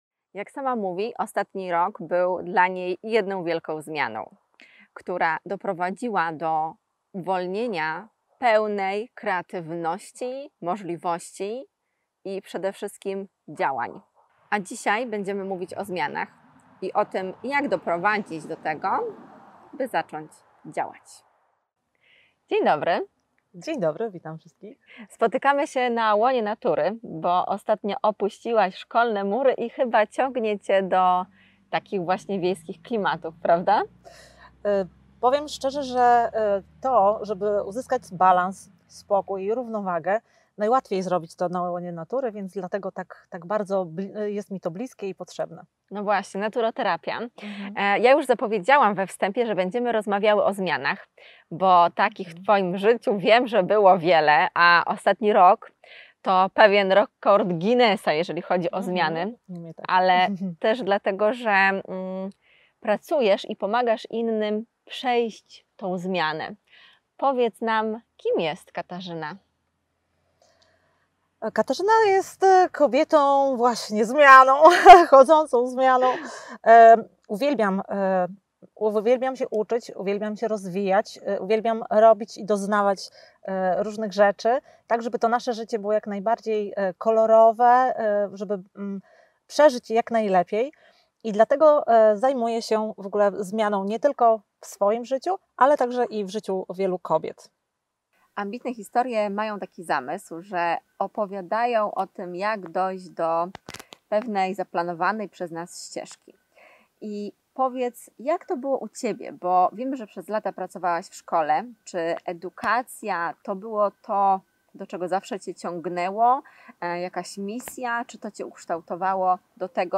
Dzisiejsza rozmowa będzie z nowoczesną kobietą, edukatorką pozytywnego myślenia i dobrej zmiany, bo mimo, że zmiana nie zawsze tak nam się kojarzy, to w większości doprowadza to czegoś nowego, a to pozytywnie wpływa na nasze życie, myślenie, odczuwanie.